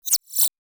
Alien Notification.wav